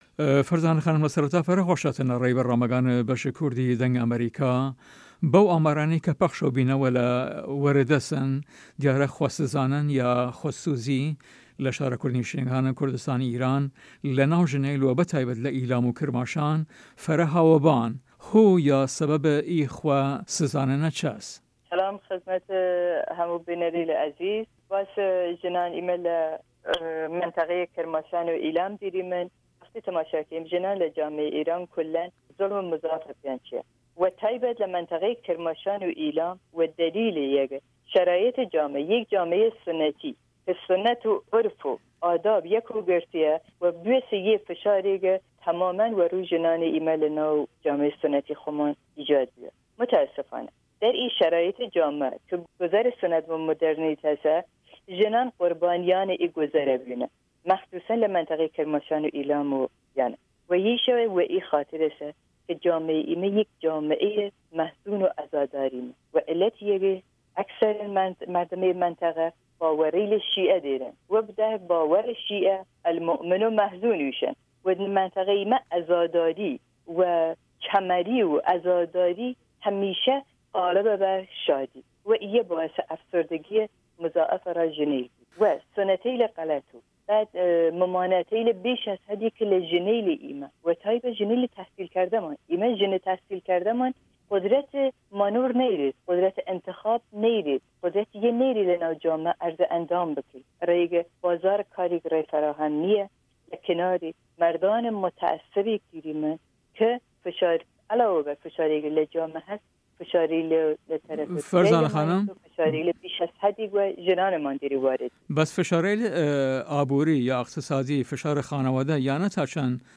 ئەم وتو وێژە بە زاراوەی ناوچەکانی ئیلام و کرماشان ئەنجام دراوە